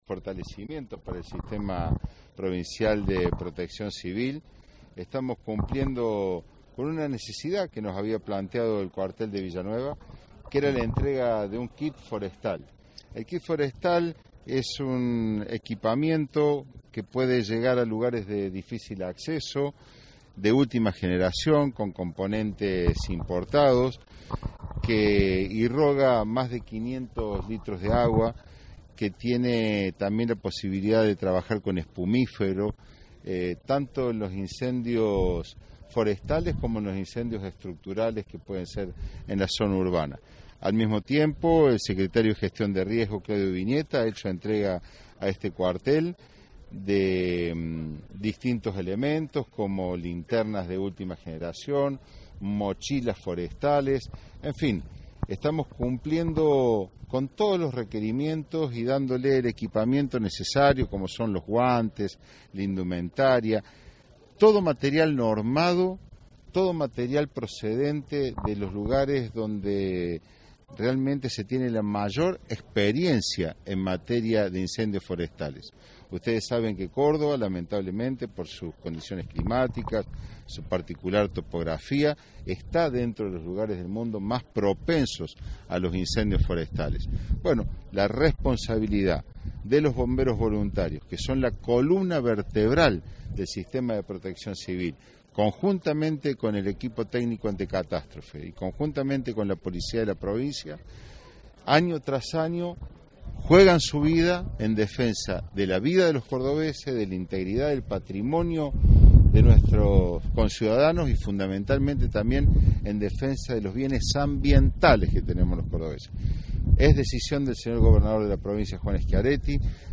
En declaraciones a la prensa y en especial a Radio Centro, el Ministro Mosquera expresaba lo siguiente: